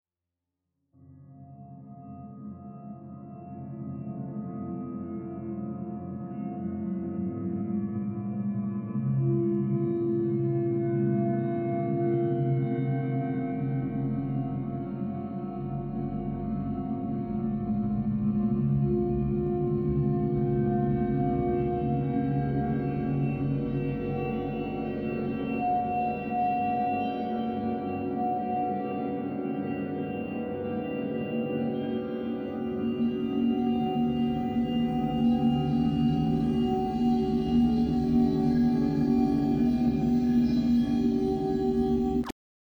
Scary
Perfect for eerie, horror, scary.
eerie horror scary synth sound effect free sound royalty free Memes